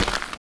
stepdirt_3.wav